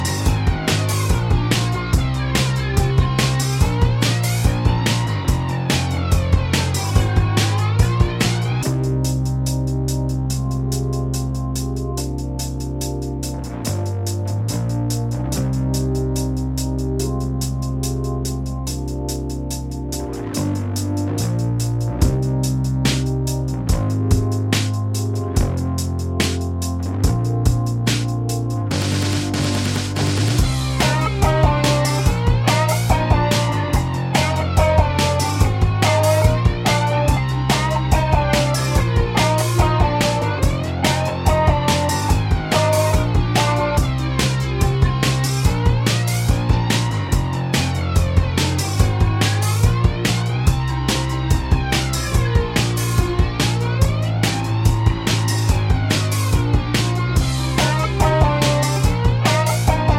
Minus Main Guitars For Guitarists 3:40 Buy £1.50